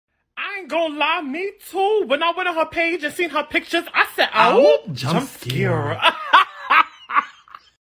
Awoop Jumpscare Sound Effect Free Download
Awoop Jumpscare